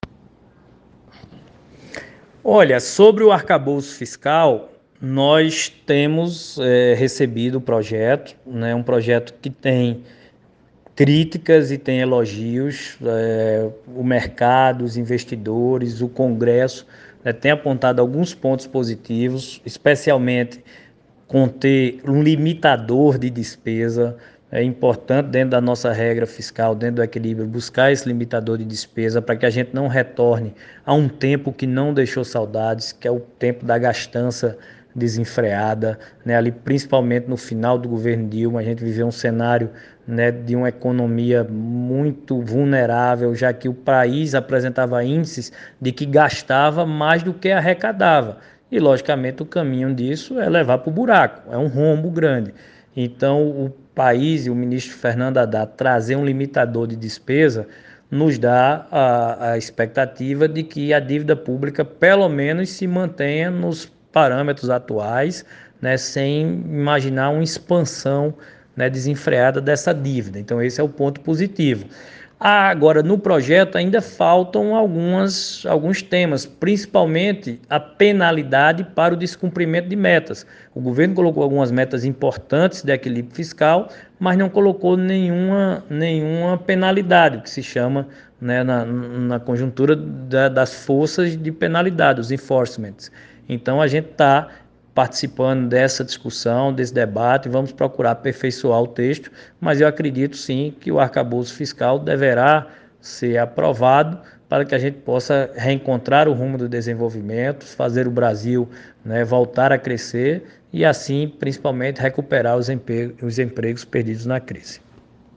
O senador Efraim Filho, líder do União Brasil, disse nesta quarta-feira (19), em entrevista à Rádio Arapuan FM, que o novo arcabouço fiscal, apresentado pela equipe...